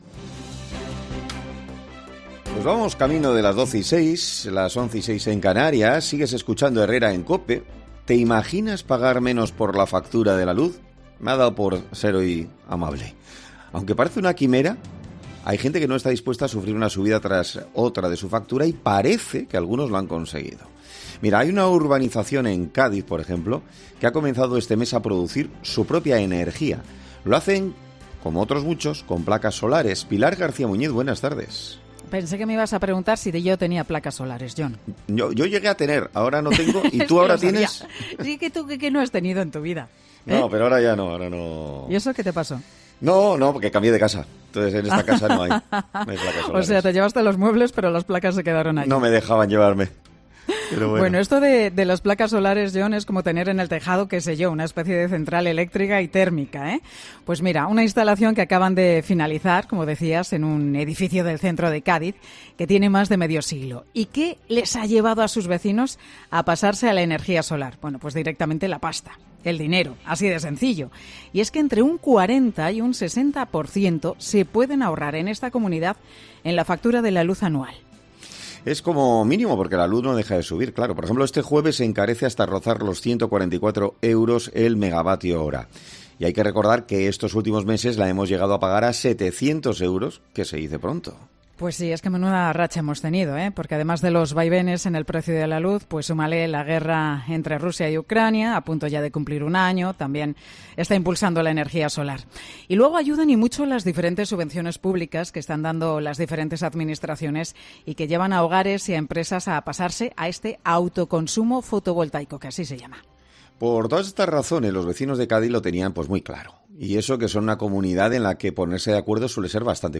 AUDIO: 'Herrera en COPE' habla con la presidenta de la comunidad de vecinos que ha instalado las placas solares y sobre las ventajas del autoconsumo...